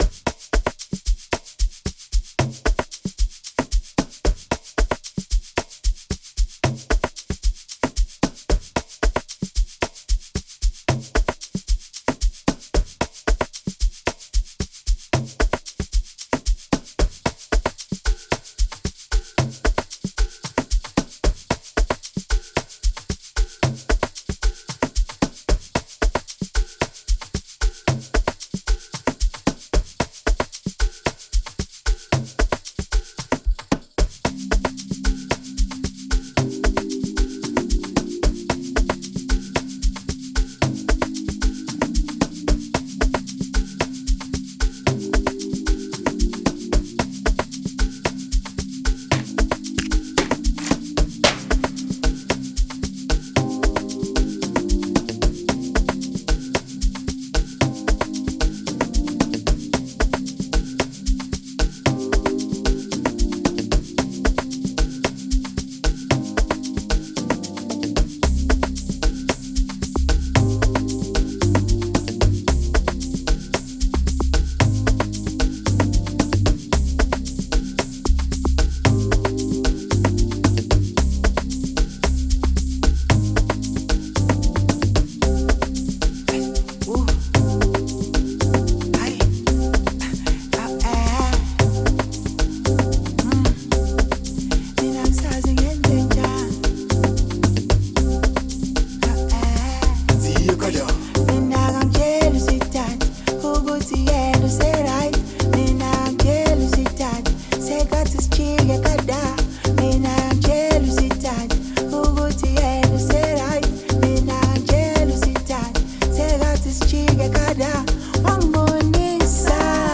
Over a beautiful tribal influenced production
Amapiano genre